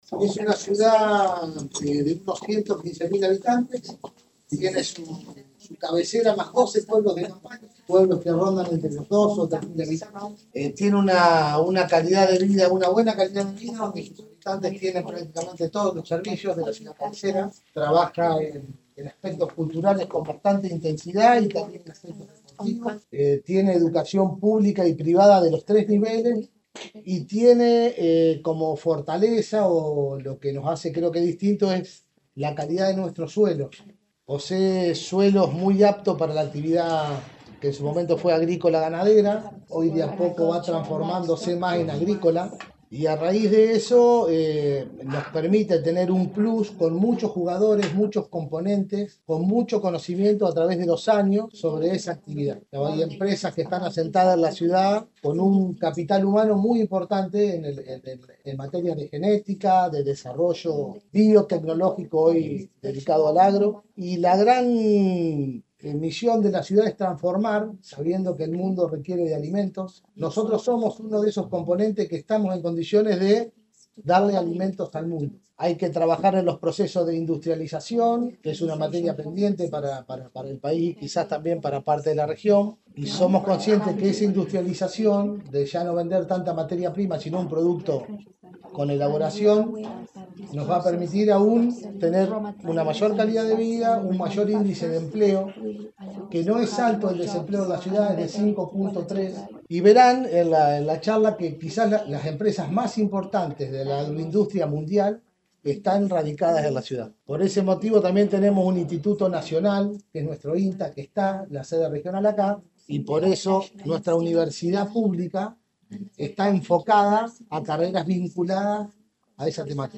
Con una reunión realizada en la planta alta de la Biblioteca Municipal Dr Joaquín Menéndez, compartieron una recepción, dónde se plantearon diferentes realidades.